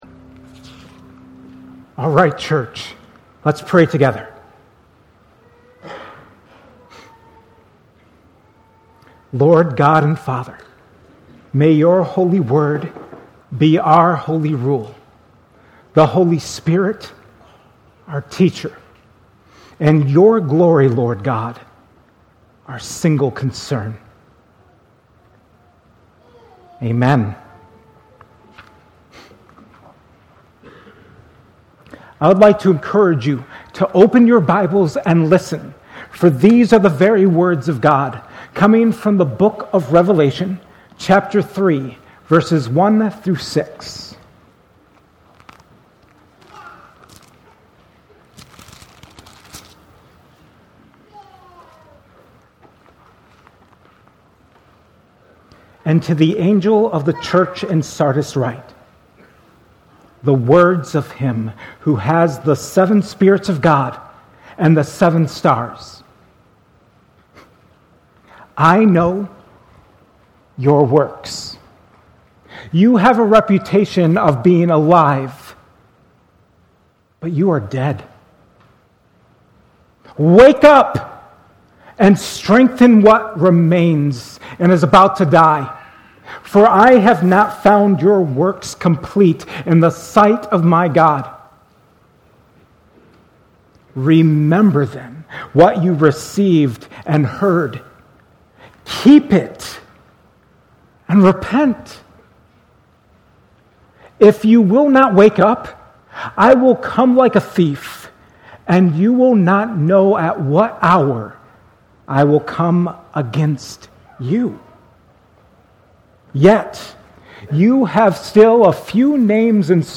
at Cornerstone Church in Pella